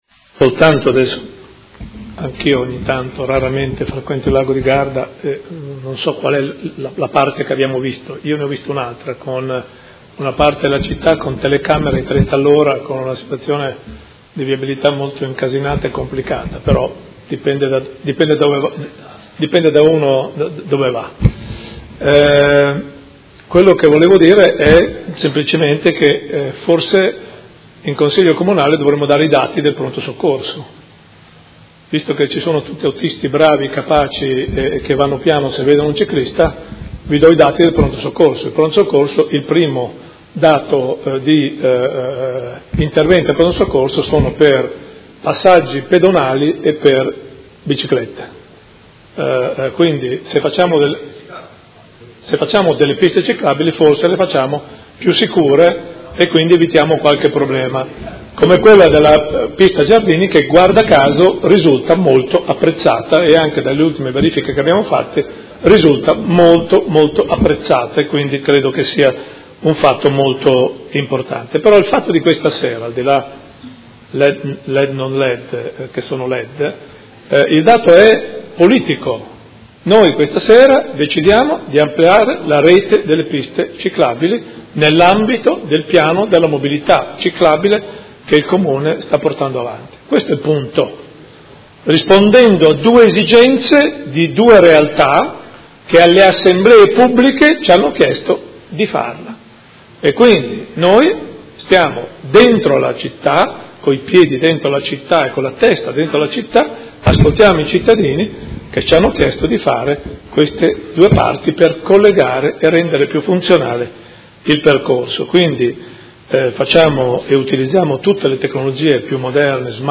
Sindaco
Seduta del 17/11/2016.